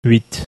hu in huit
no corresponding sound in English